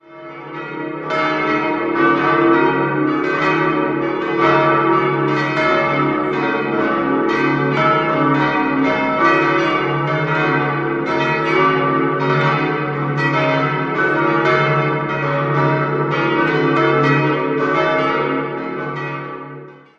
5-stimmiges Geläute: des'-es'-ges'-as'-b' Alle Glocken wurden bei der Gießerei Gebhard in Kempten gegossen: die kleine 1929, die vier anderen 1950.